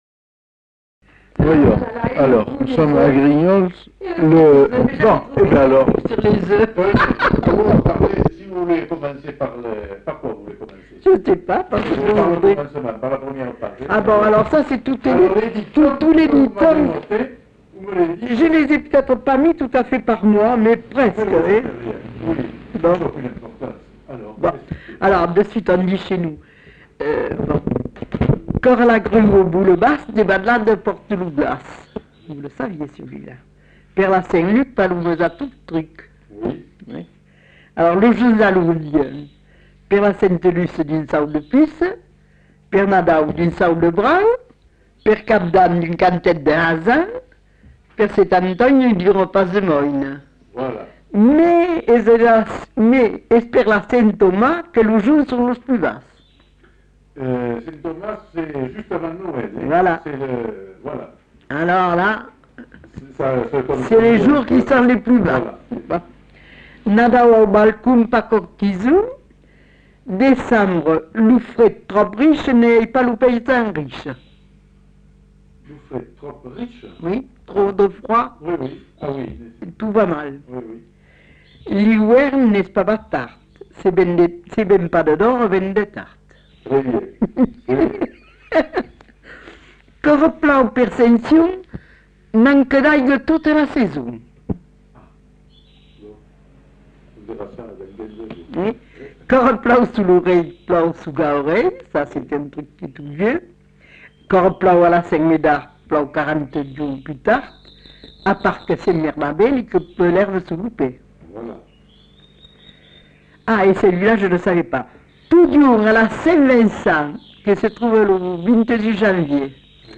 Aire culturelle : Bazadais
Lieu : Grignols
Effectif : 1
Type de voix : voix de femme
Production du son : lu
Classification : proverbe-dicton